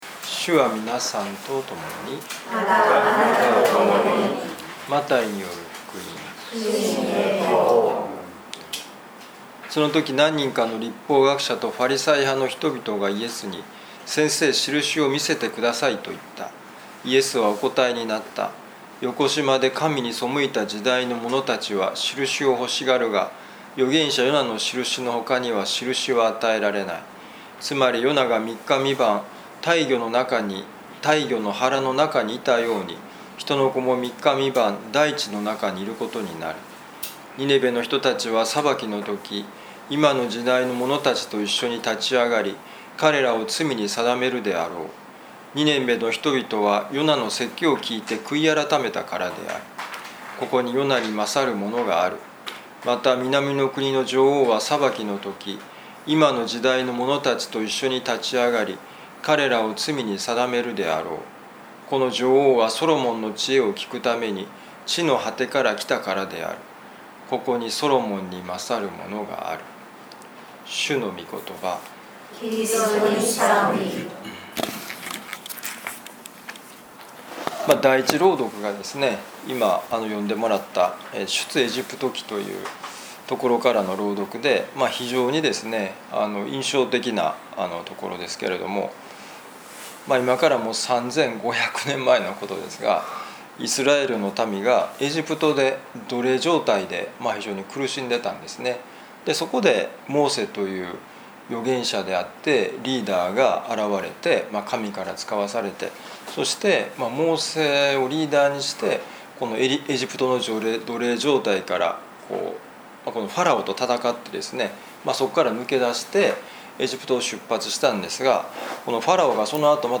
マタイ福音書 12章38-42節「囚われの海を渡る」2025年７月21日いやしのミサ旅路の里